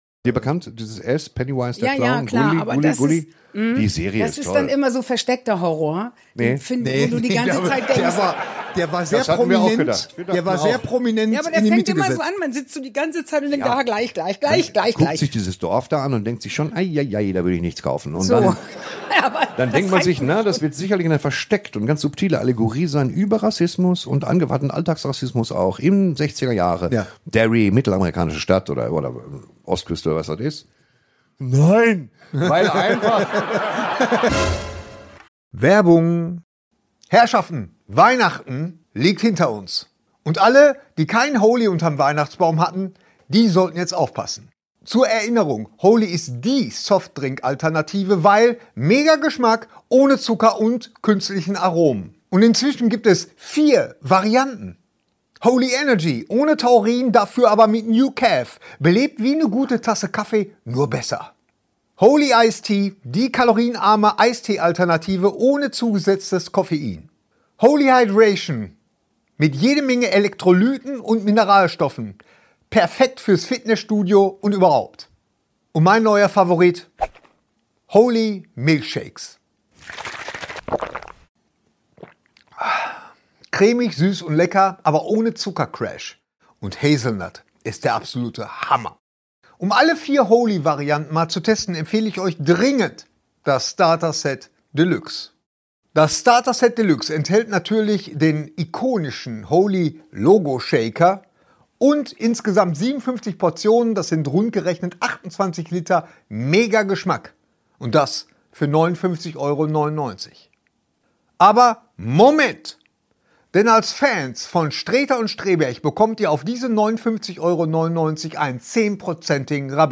Live aus dem Schmidtchen in Hamburg würdigen Torsten und Gerry zunächst das Lebenswerk von Regie-Legende ROB REINER (Harry und Sally, Misery). Danach wird es wild: Die wunderbare INA MÜLLER verrät den beiden Pansen u.a. warum sie mal gerne Horror Filme geschaut hat, und warum jetzt nicht mehr, und der großartige REINER SCHÖNE nimmt sie mit auf eine Reise durch seine beeindruckende Karriere von HAIR bis OPTIMUS PRIME und alles zwischendrin.